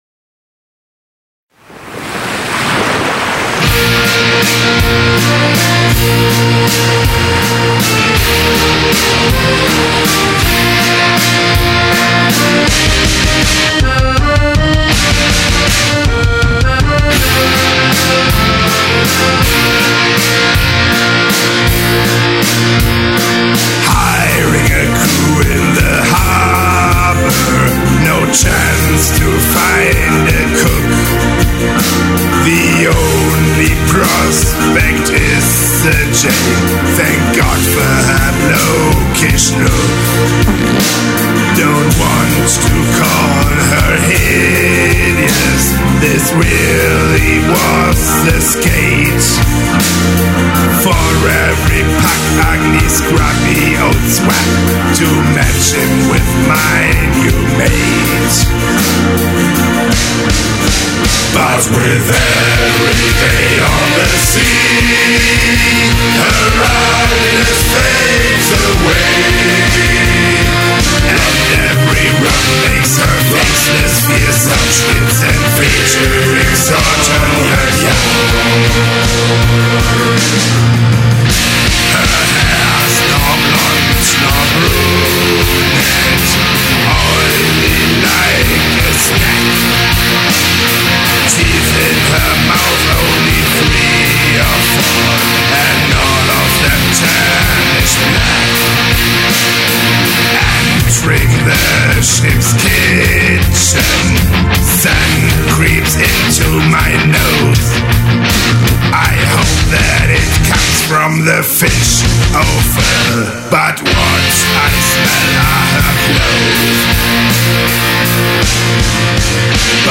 Leadvocals und Melodie